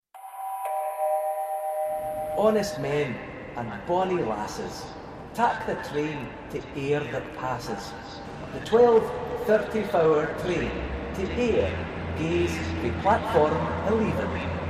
Train announcement made in Scots by Robert Burns actor